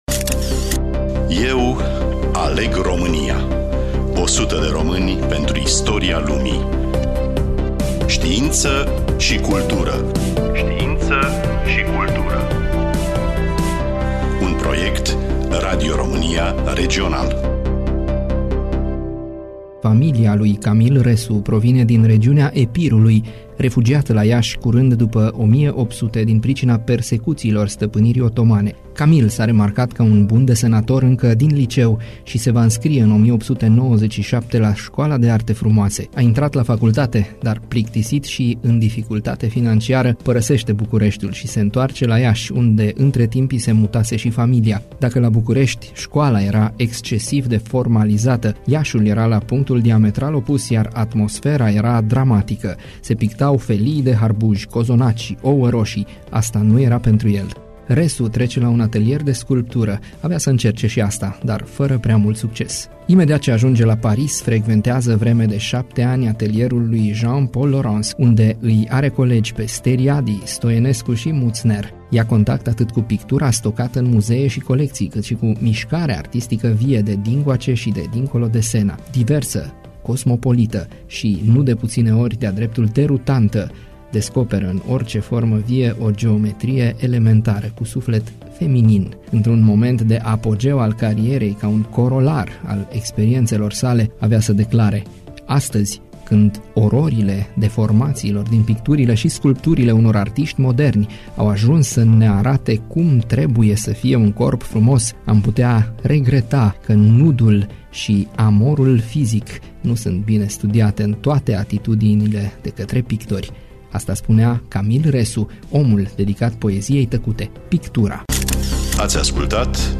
Studioul Radio Timișoara
Voiceover